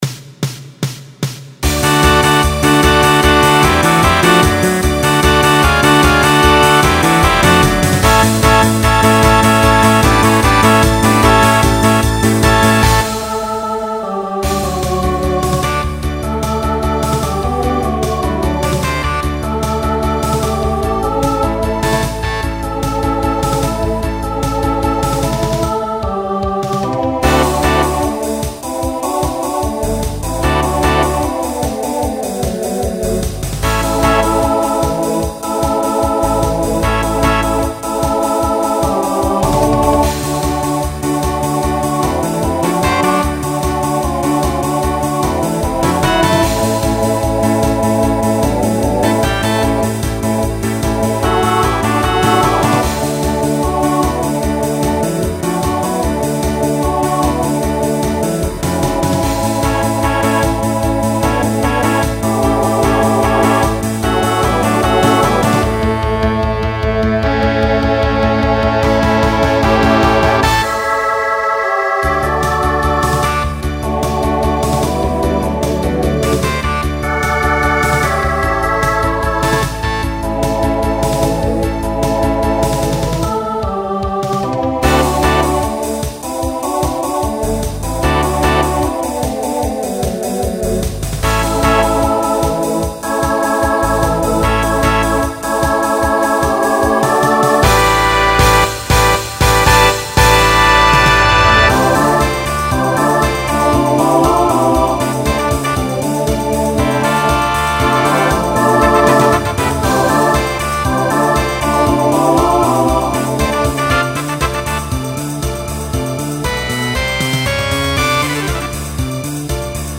Genre Disco , Rock
Story/Theme Voicing SATB